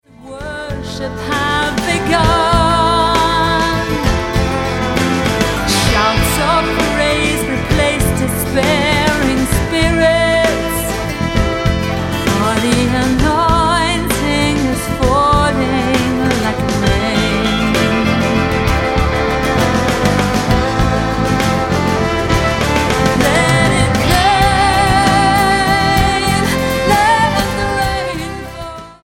STYLE: Celtic